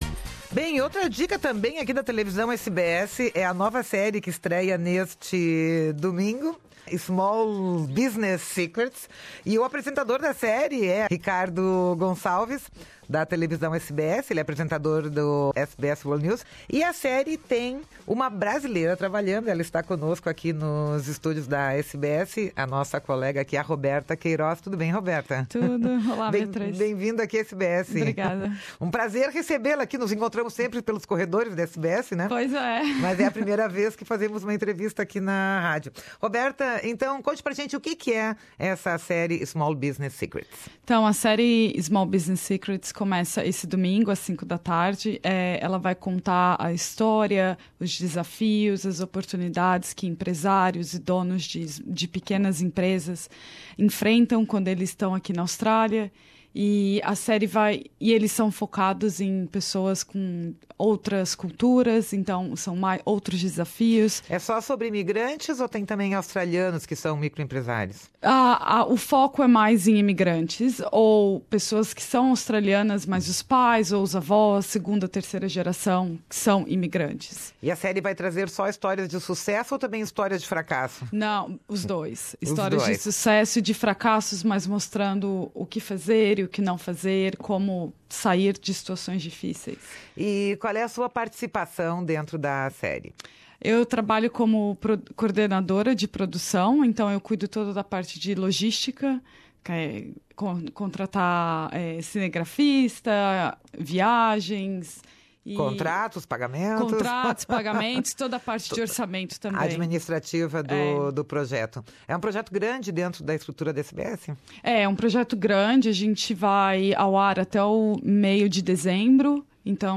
Nesta entrevista